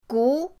gu2.mp3